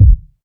KICK.59.NEPT.wav